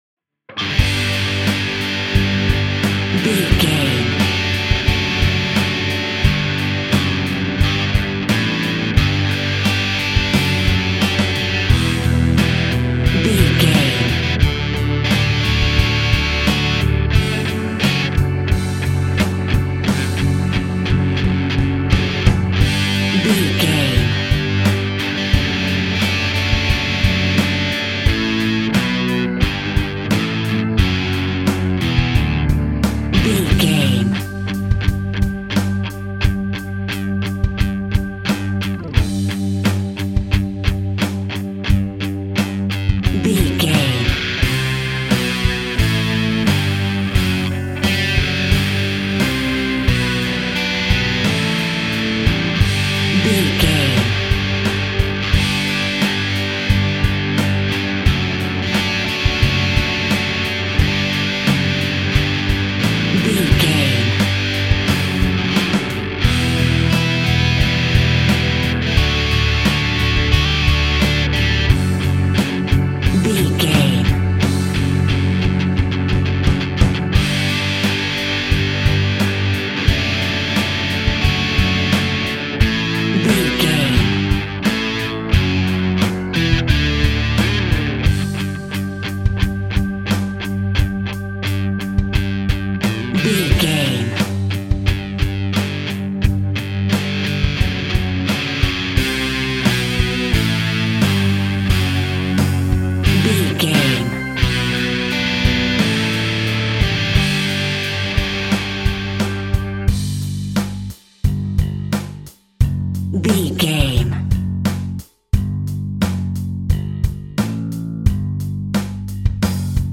Ionian/Major
D
Fast
energetic
uplifting
electric guitar
bass guitar
drums
heavy rock
distortion
Instrumental rock